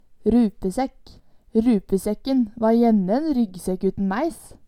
rupesekk - Numedalsmål (en-US)